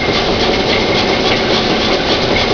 conveyor.wav